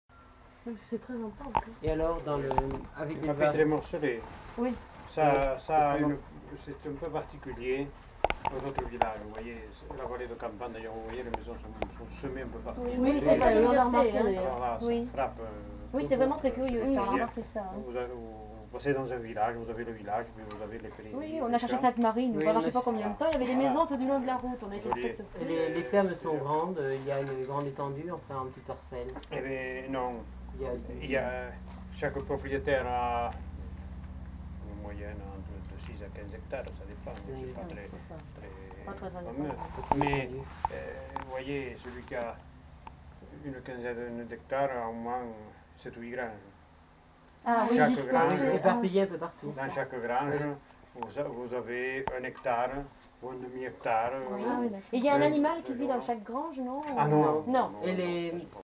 Aire culturelle : Bigorre
Lieu : Campan
Genre : témoignage thématique